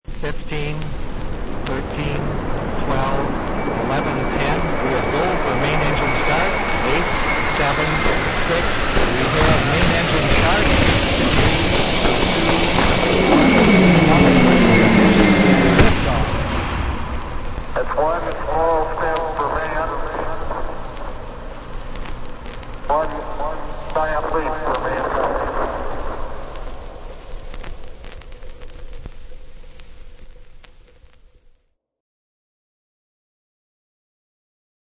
Download Rocket Launch sound effect for free.
Rocket Launch